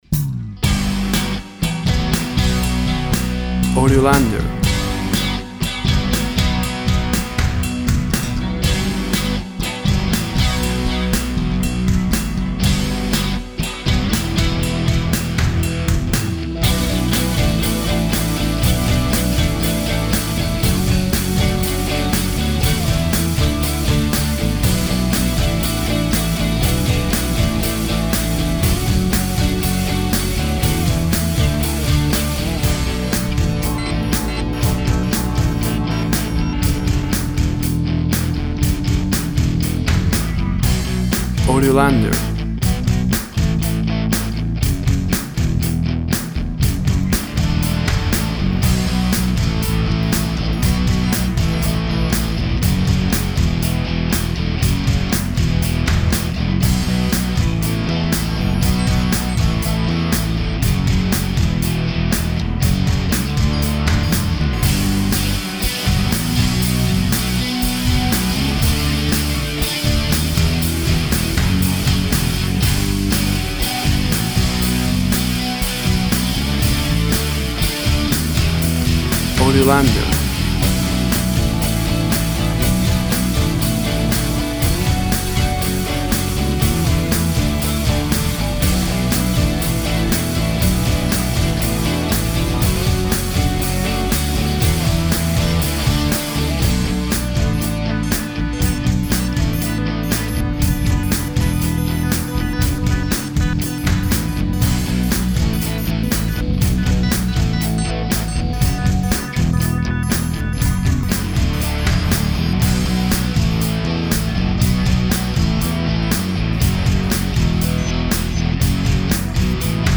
WAV Sample Rate 16-Bit Stereo, 44.1 kHz
Tempo (BPM) 115